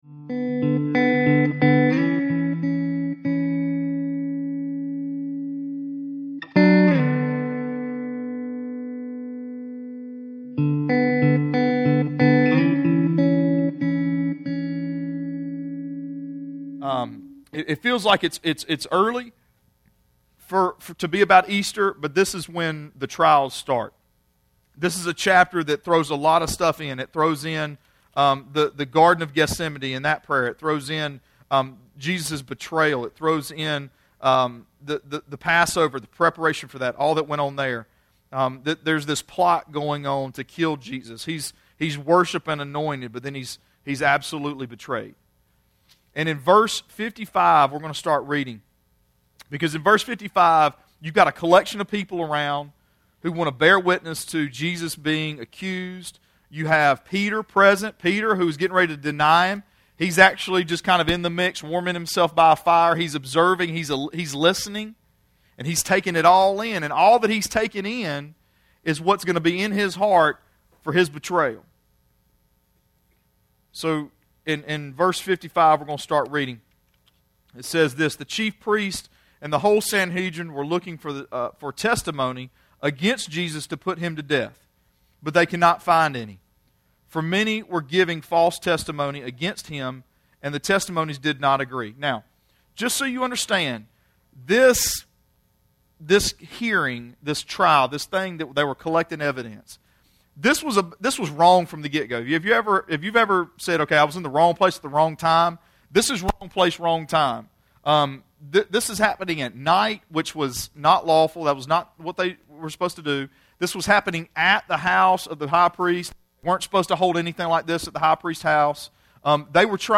Sermons Archive - REEDY FORK COMMUNITY CHURCH